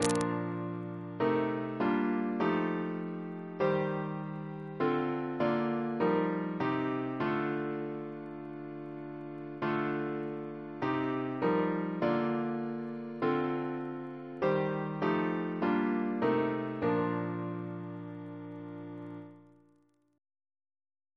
Double chant in B♭ Composer